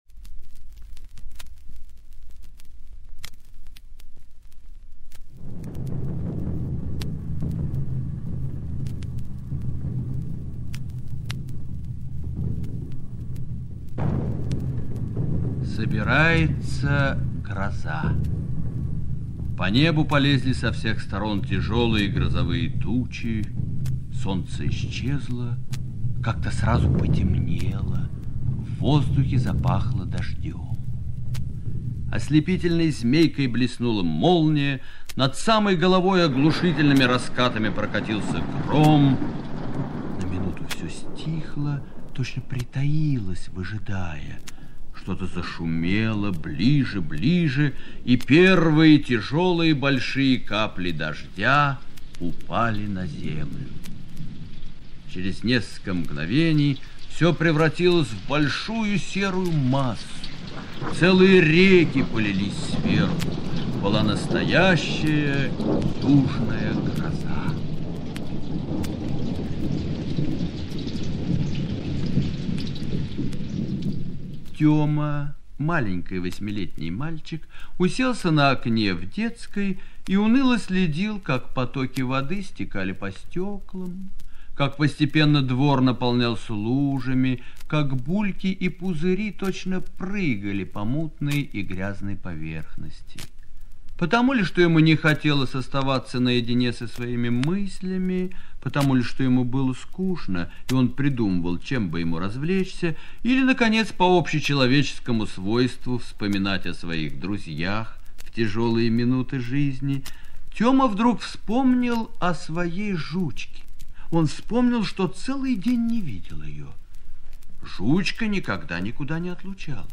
Тёма и Жучка - аудиокнига Н.Г. Гарина-Михайловского